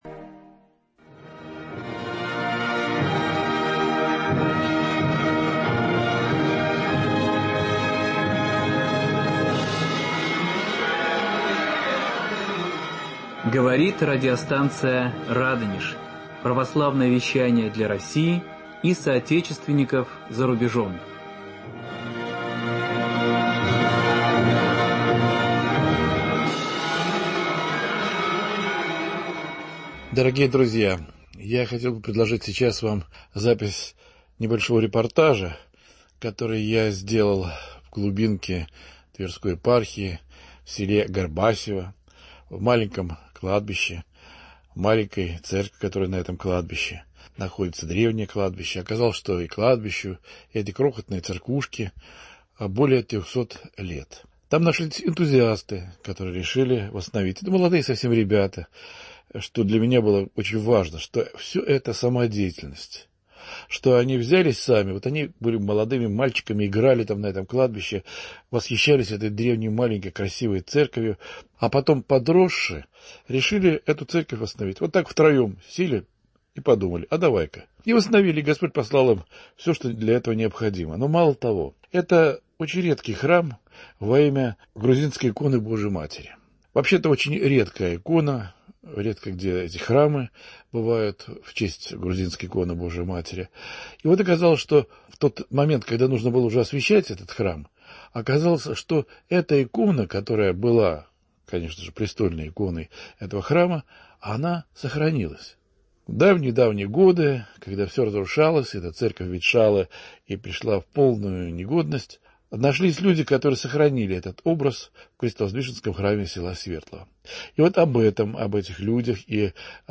В нашем эфире репортажа из села Горбасьево.